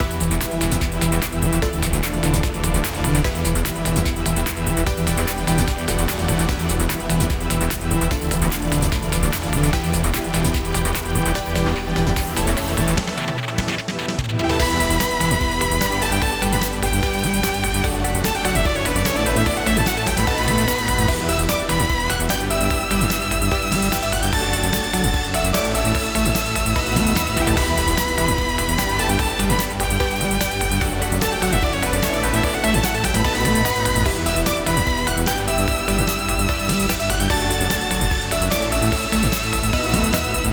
80s JRPG – Soundtracks
This composition captures the essence of classic 80s JRPG soundtracks, featuring nostalgic synth melodies, rich harmonies, and expressive chiptune elements. Inspired by the golden era of role-playing games, the piece evokes a sense of adventure and emotion, bringing retro game worlds to life with an authentic vintage sound.